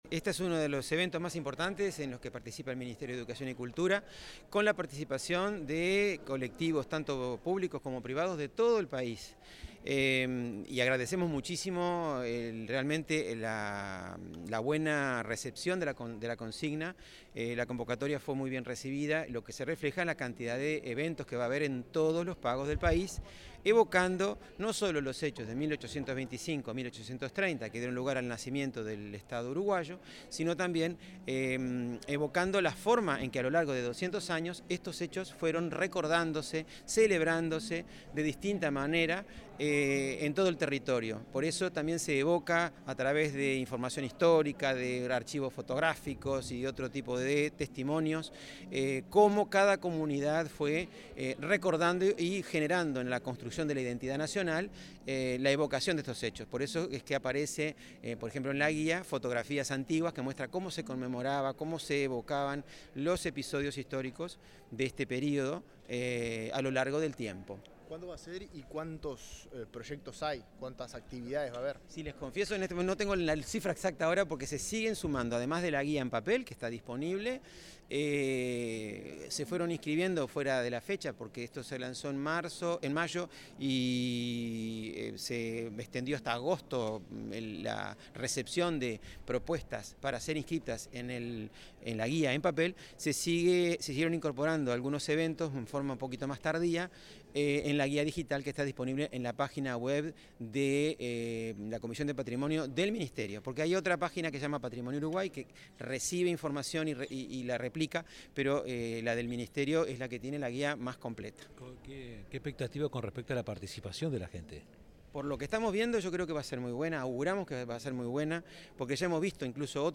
Declaraciones del director de la Comisión del Patrimonio Cultural de la Nación, Marcel Suárez